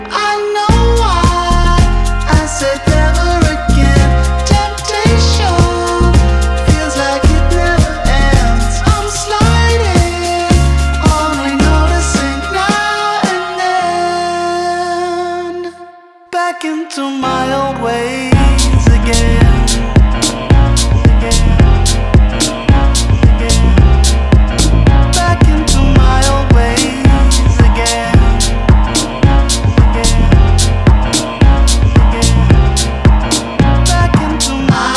Alternative Dance
Жанр: Танцевальные / Альтернатива